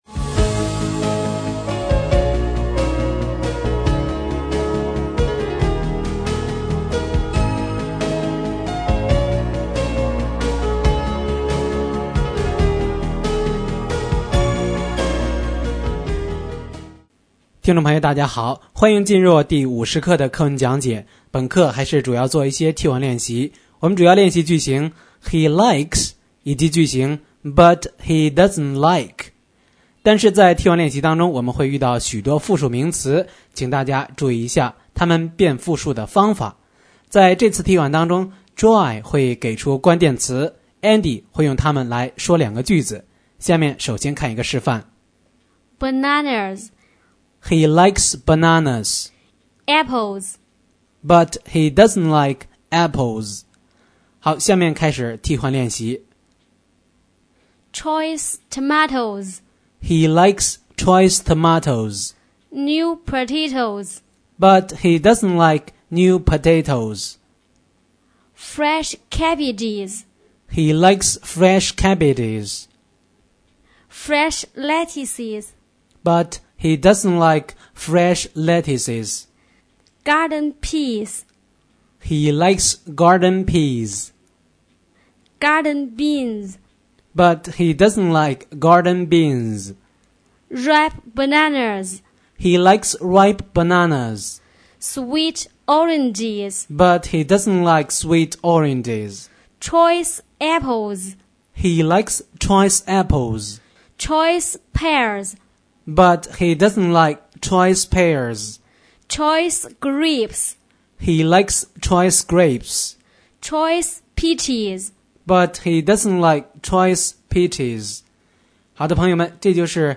新概念英语第一册第50课【课文讲解】